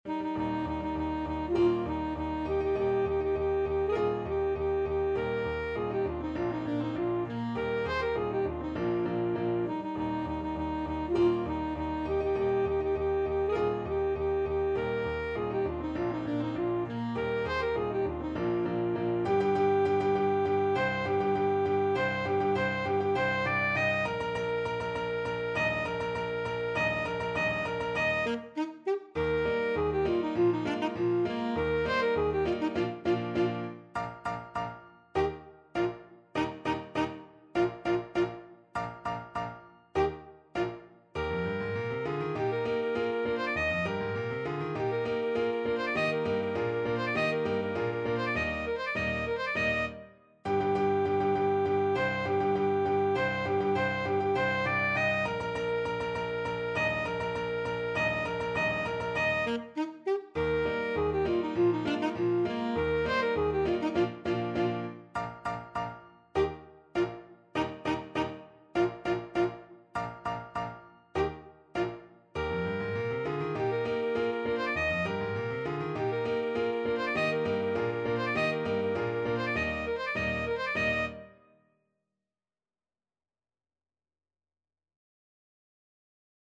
Alto Saxophone
Eb major (Sounding Pitch) C major (Alto Saxophone in Eb) (View more Eb major Music for Saxophone )
2/2 (View more 2/2 Music)
Bb4-Eb6
March = c.100
Classical (View more Classical Saxophone Music)
yorckscher_marsch_ASAX.mp3